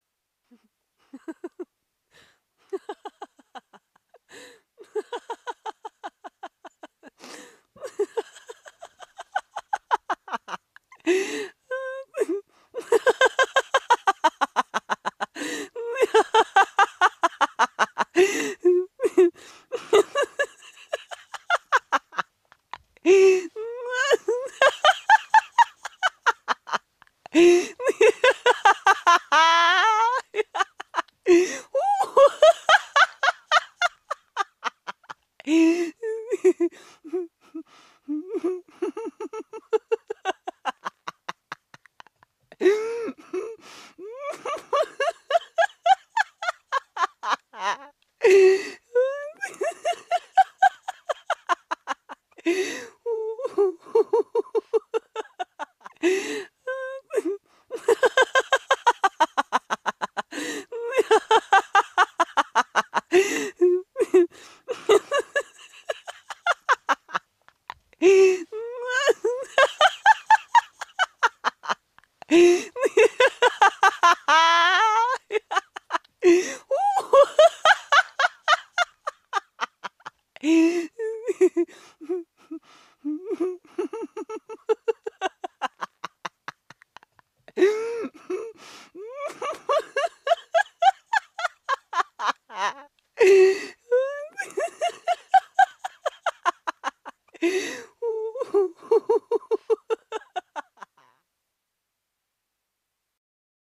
جلوه های صوتی
دانلود صدای خنده زن از ساعد نیوز با لینک مستقیم و کیفیت بالا